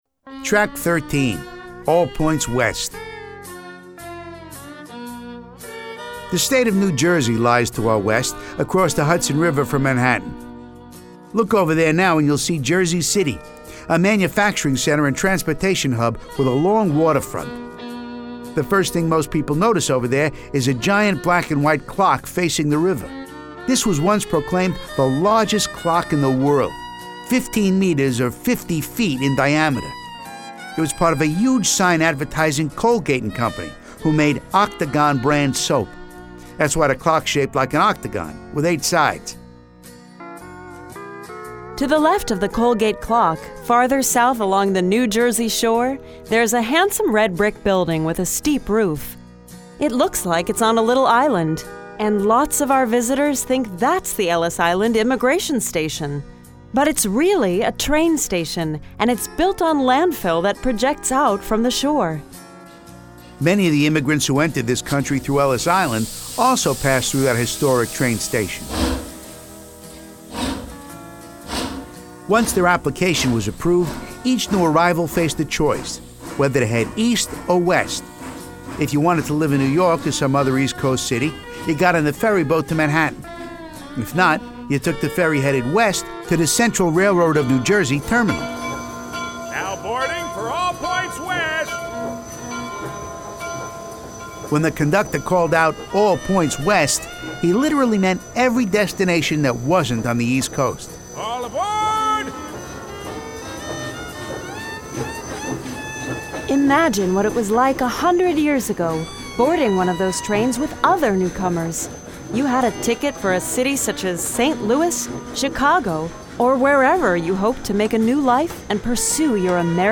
GPS-Triggered Audio Tour Samples